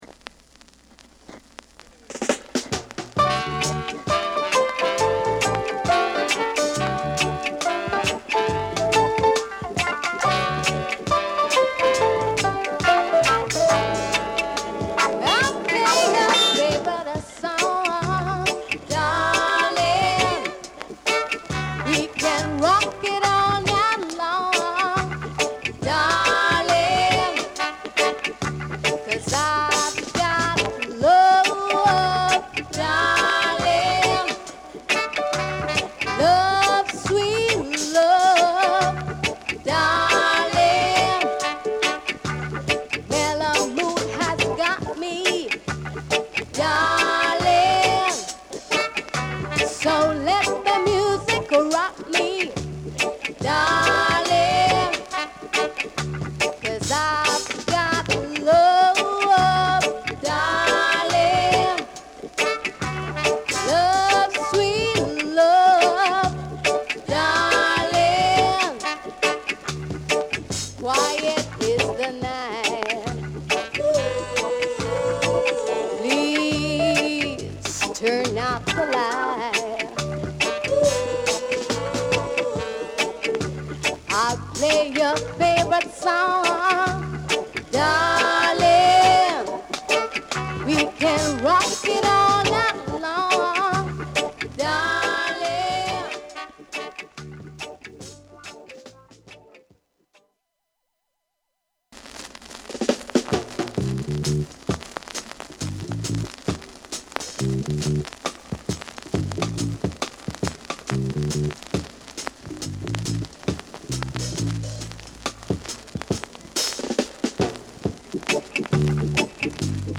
Genre: Reggae / Roots